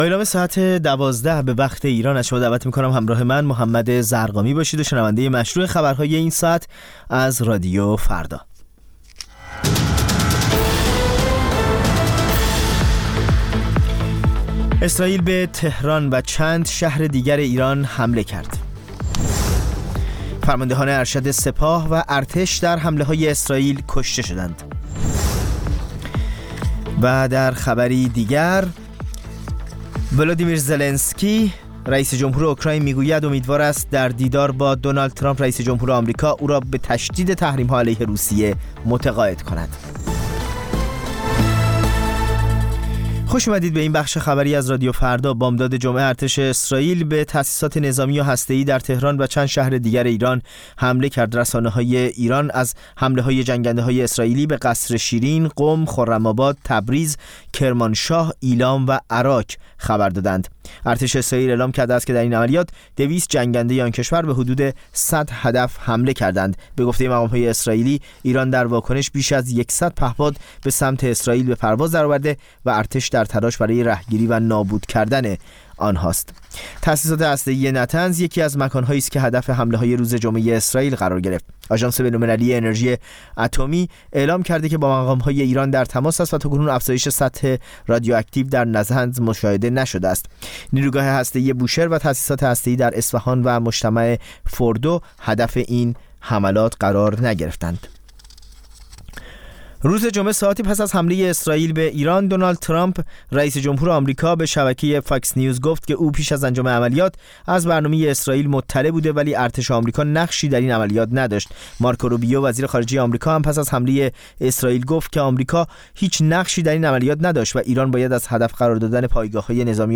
سرخط خبرها ۱۲:۰۰